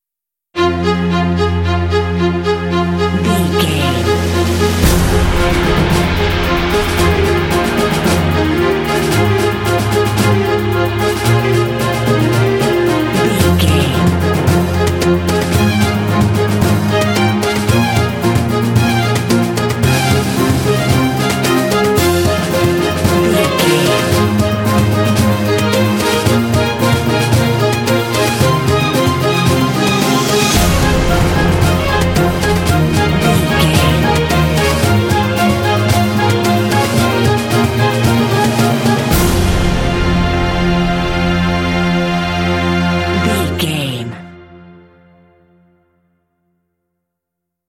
Epic / Action
Aeolian/Minor
dramatic
foreboding
tension
percussion
strings
flute
oboe
cinematic
film score